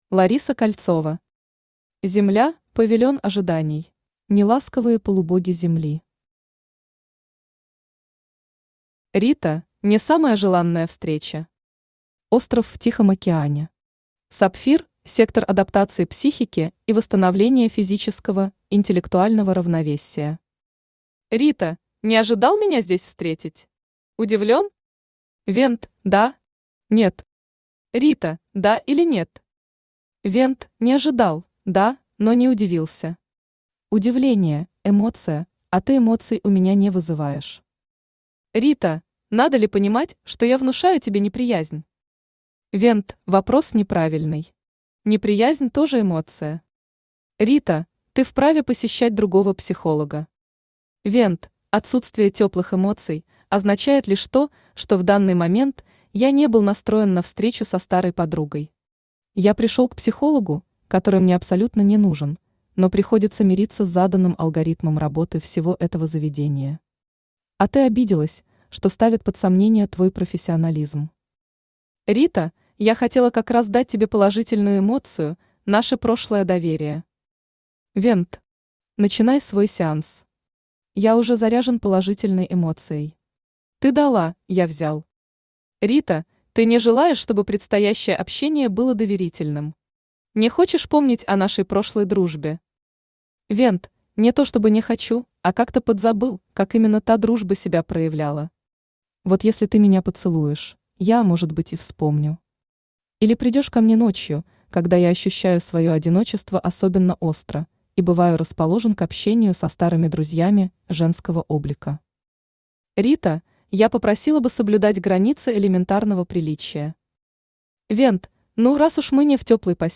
Аудиокнига Земля – павильон ожиданий | Библиотека аудиокниг
Aудиокнига Земля – павильон ожиданий Автор Лариса Кольцова Читает аудиокнигу Авточтец ЛитРес.